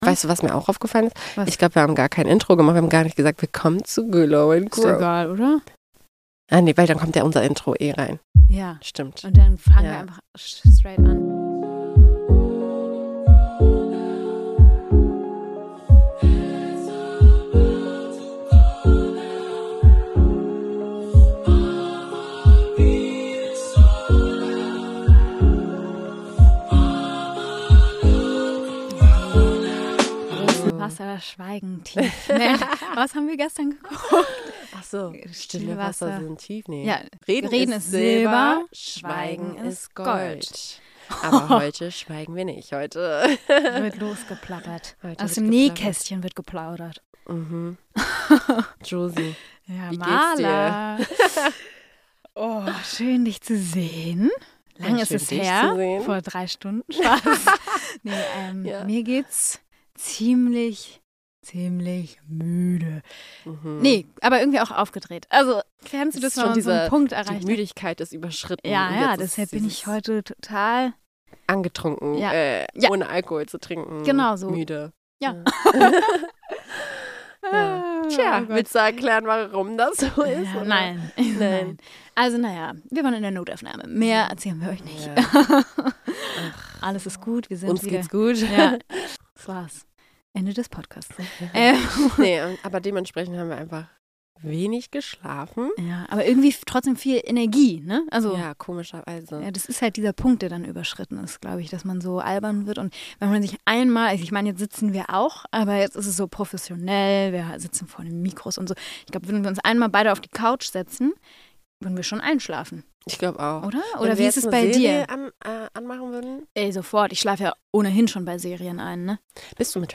Zwei Introvertierte machen einen Podcast, kann das gut gehen?